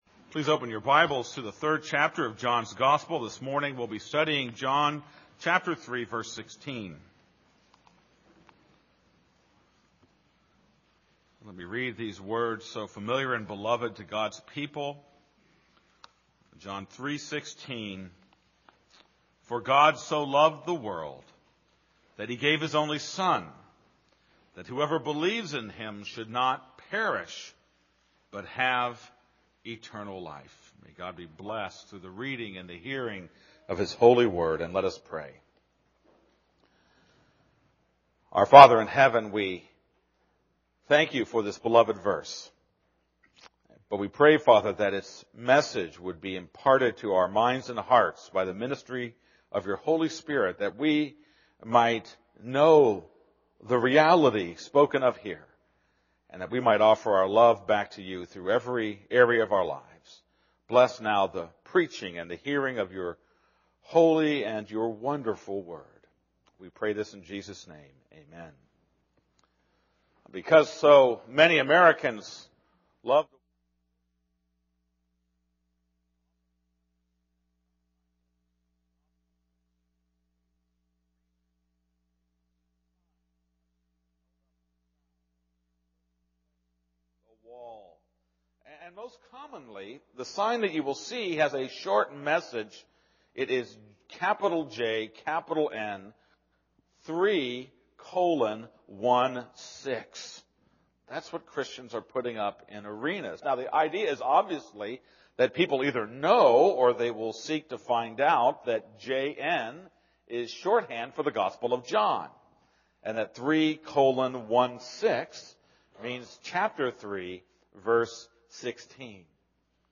This is a sermon on John 3:16.